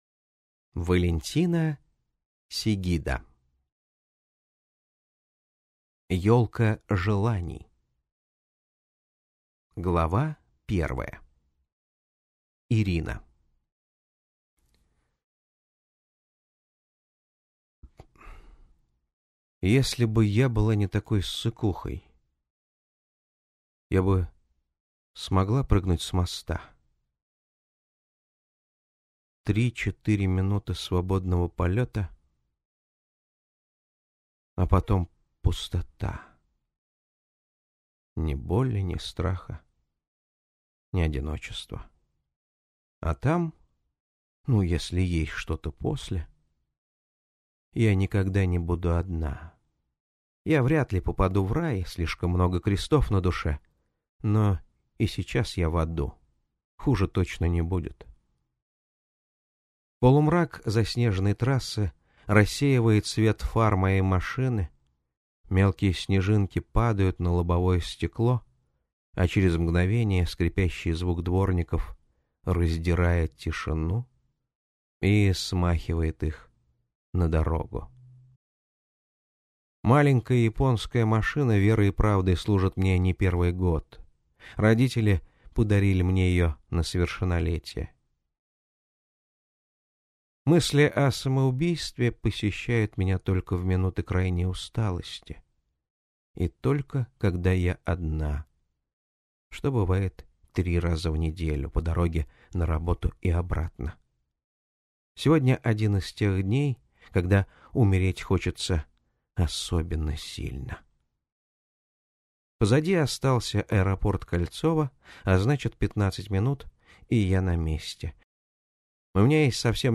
Аудиокнига Ёлка желаний | Библиотека аудиокниг
Прослушать и бесплатно скачать фрагмент аудиокниги